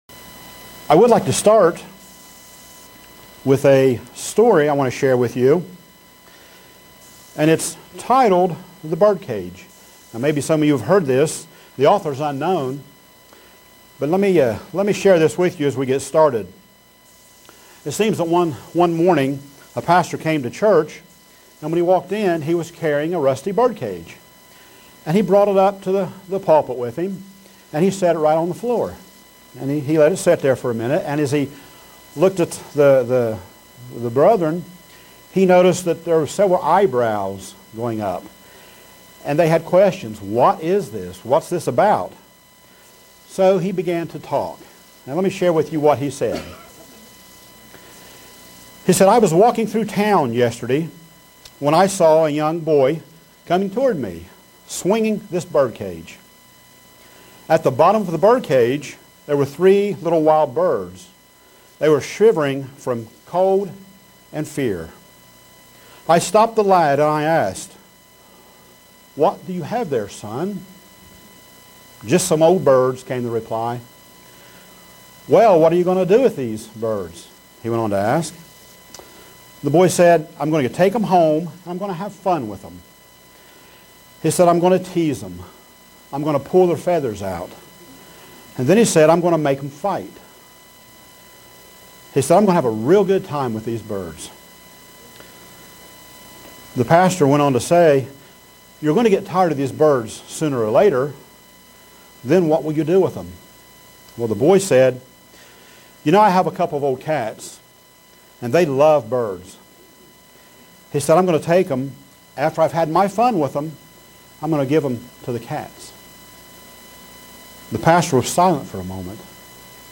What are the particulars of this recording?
Given in Buffalo, NY Elmira, NY